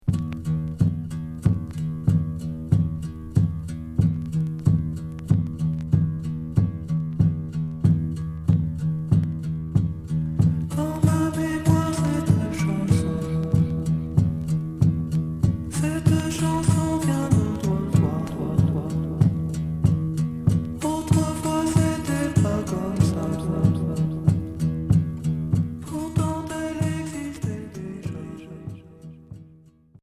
Progressif Troisième 45t retour à l'accueil